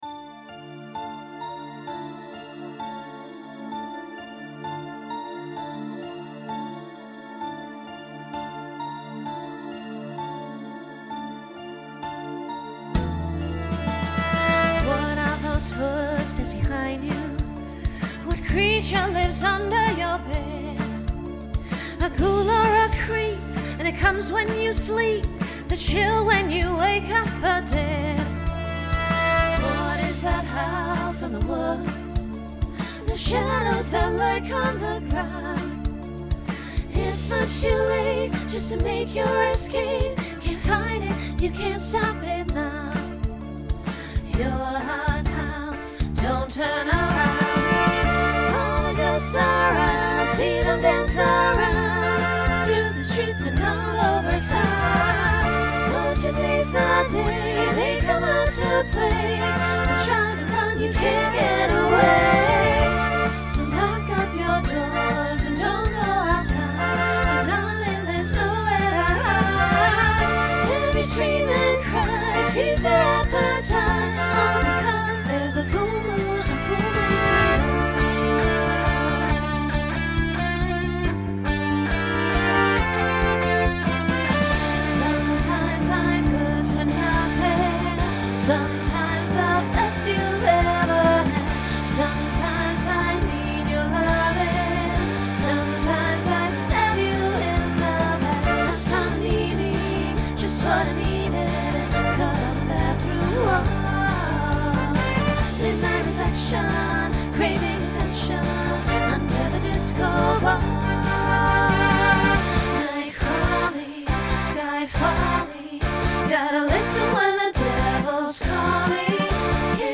Genre Pop/Dance , Rock
Story/Theme Voicing SSA